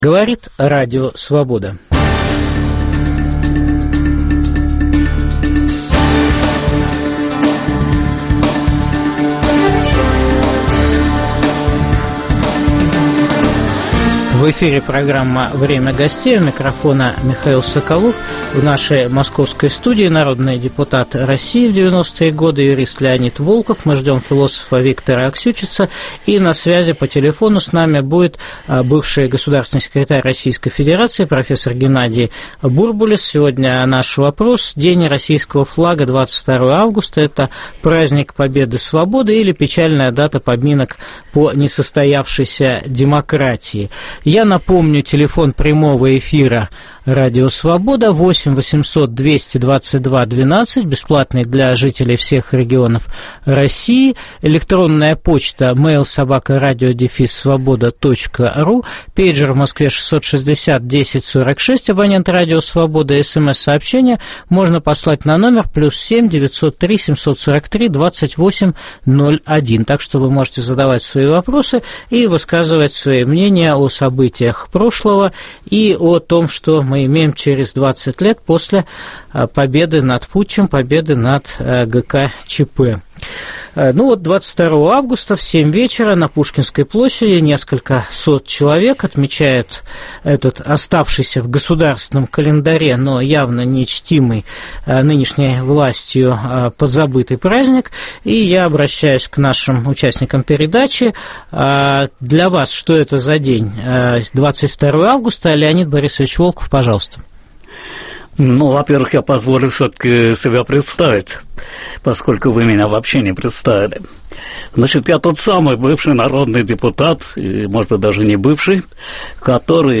День российского флага: праздник победы свободы или печальная дата поминок по несостоявшейся демократии? Дискутируют бывший Госсекретарь России Геннадий Бурбулис и народные депутаты РФ Леонид Волков и Виктор Аксючиц.